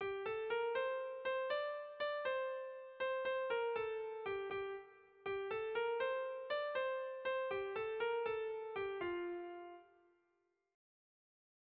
Erromantzea
A-A2